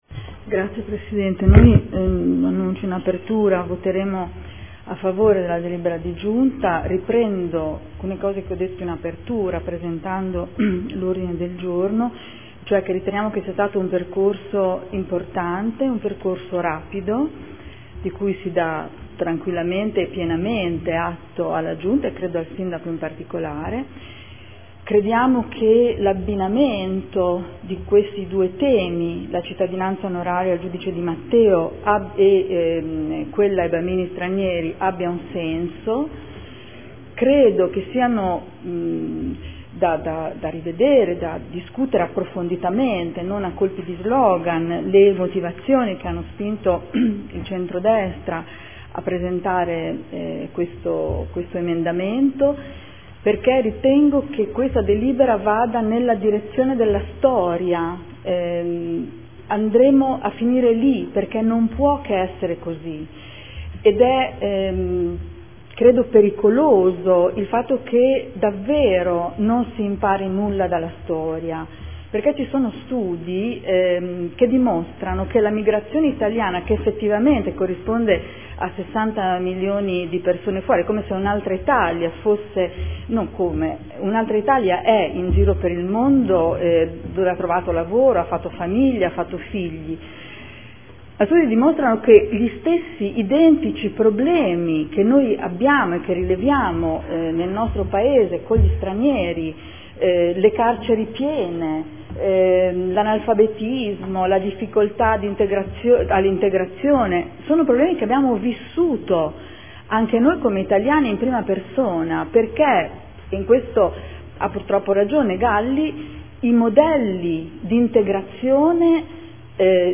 Adriana Querzè — Sito Audio Consiglio Comunale